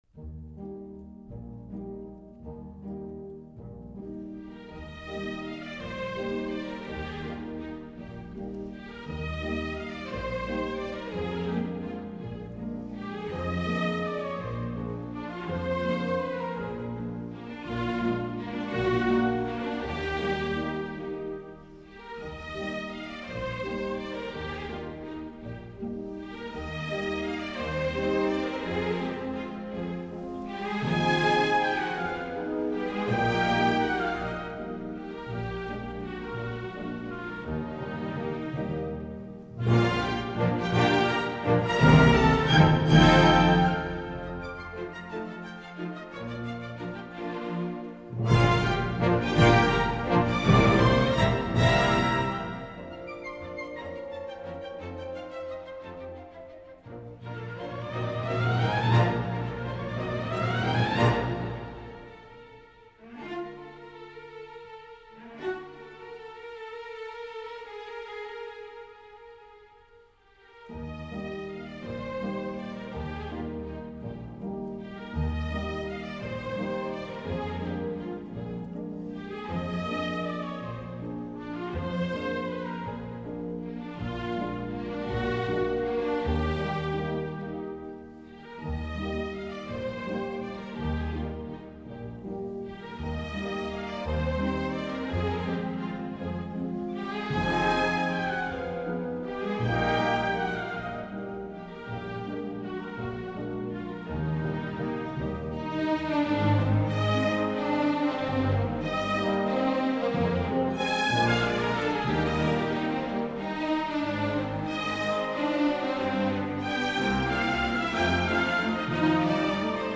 Incontro alla Scala con Nicoletta Manni
Una interessante chiacchierata in cui rivela i tanti colori del suo mondo visto sulle punte, alcuni dei rilevanti traguardi della sua affascinante carriera ma anche qualcosa di più sul magico universo della danza.